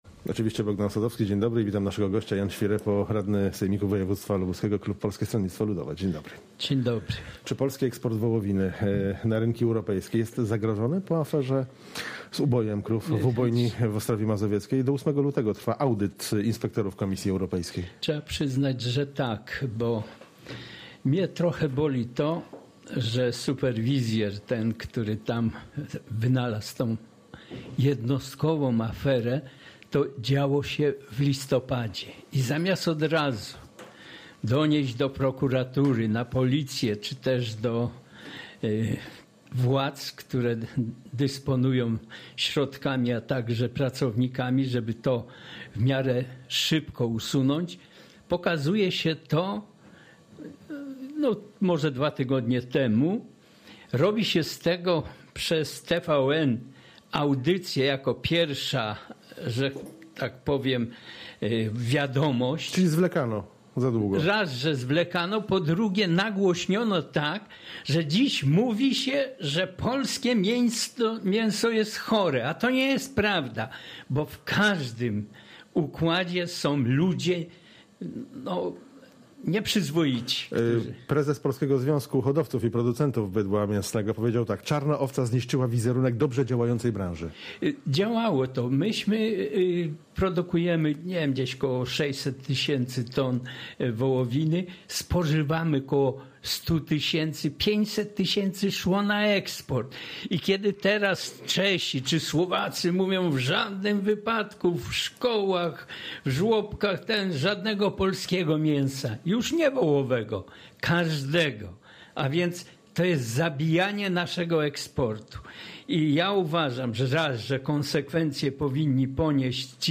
Z radnym sejmiku wojewódzkiego z klubu PSL rozmawia